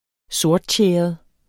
Udtale [ -ˌtjεˀʌð ]